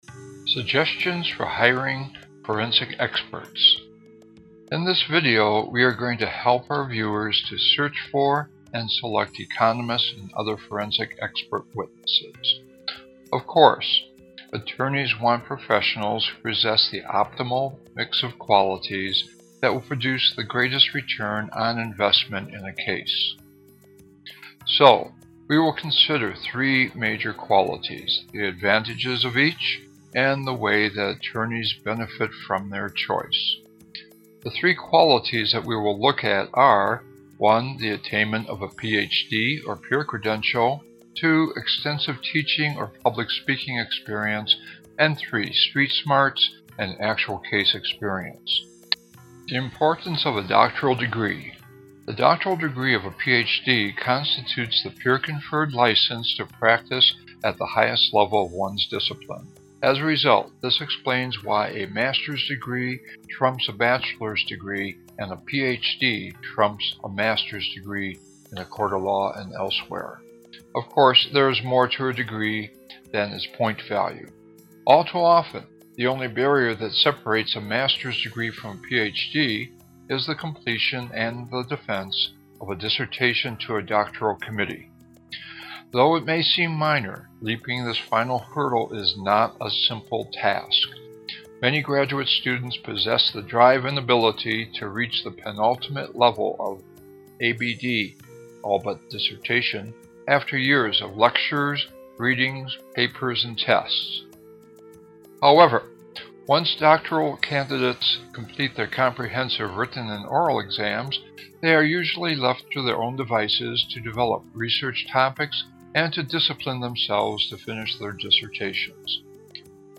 Hiring Forensic Experts--audiobook